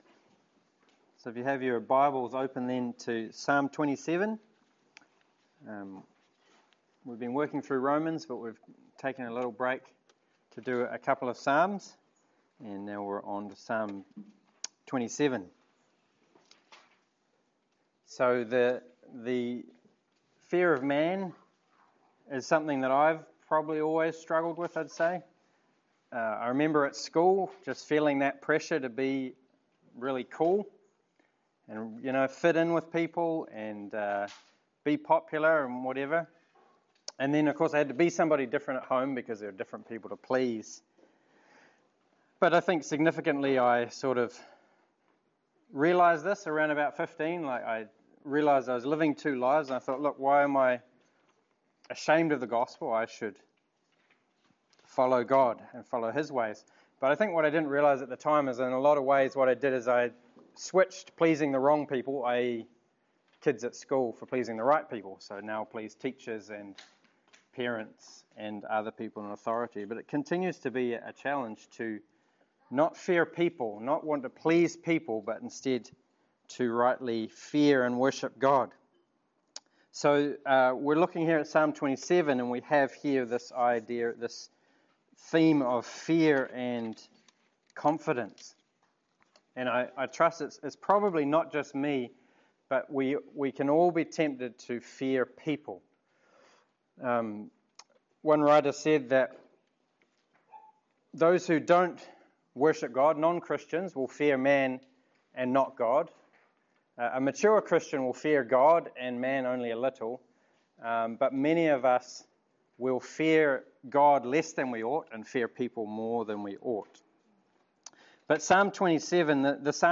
Psalm 27 Service Type: Sermon It is so common for us to be driven by fears and anxieties instead of faith in God.